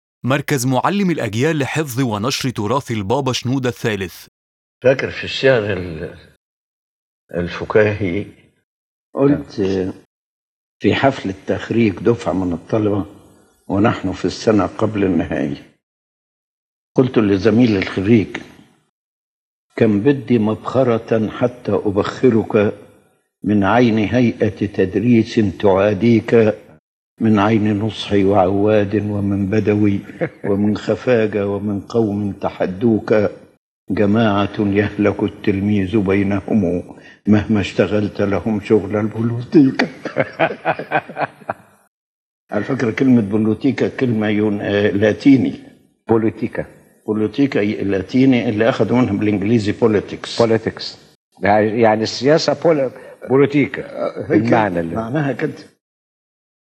In this talk, Pope Shenouda III speaks about the humorous side of his poetic talent, using lighthearted verses to reflect daily life situations, especially in the context of student life and interactions with teachers.